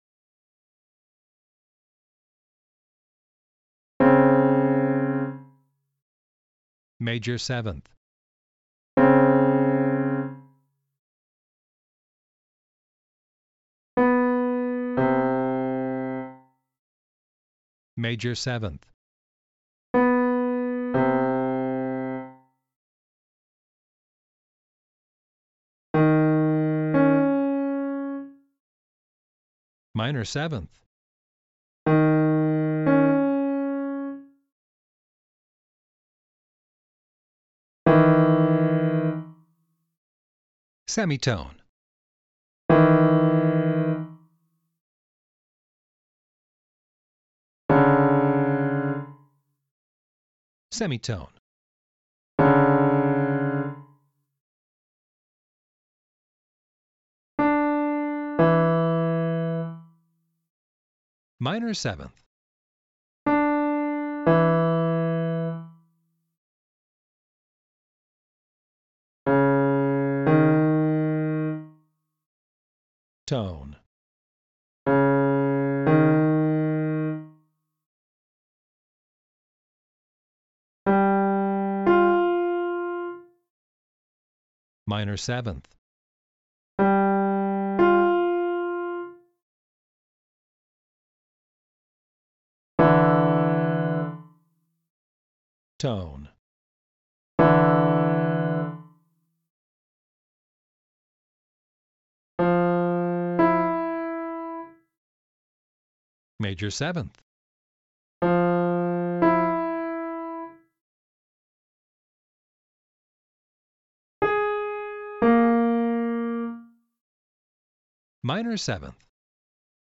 This module lets you practice the intervals in each of the three forms and also in combinations of the forms: “melodic” includes ascending and descending, and “mixed” includes all three.
Each time an interval is played, it is then announced so you know which intervals you’re hearing.
Training-Exercise-5.-STTm7M7-mixed.mp3